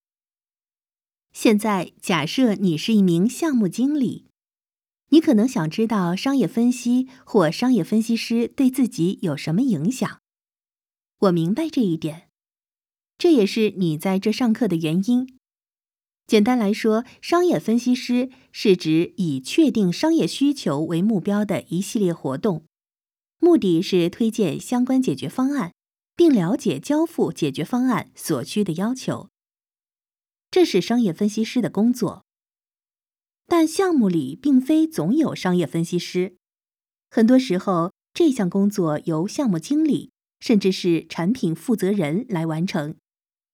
Chinese_Female_043VoiceArtist_4Hours_High_Quality_Voice_Dataset